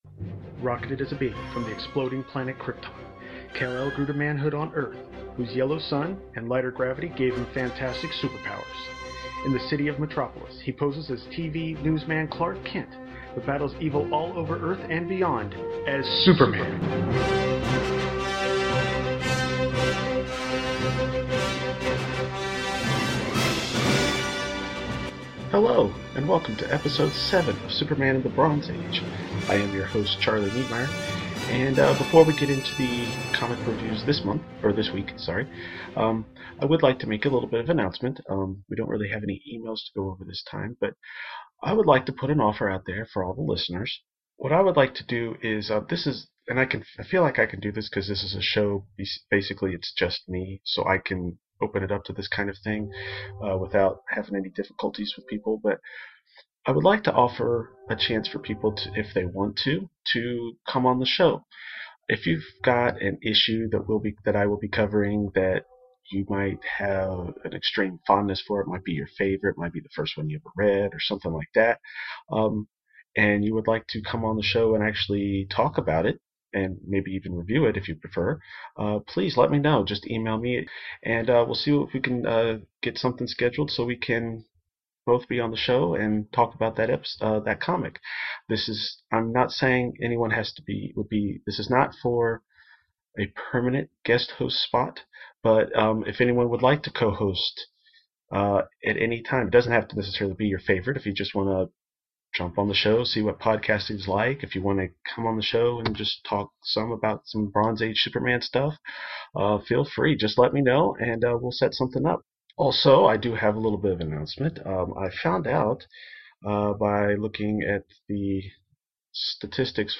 In this episode, Superman #235, World’s Finest #201 and Action Comics #398 are reviewed, plus if you listen close, you’ll hear a cameo from my cat.